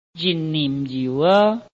臺灣客語拼音學習網-客語聽讀拼-海陸腔-鼻尾韻
拼音查詢：【海陸腔】nim ~請點選不同聲調拼音聽聽看!(例字漢字部分屬參考性質)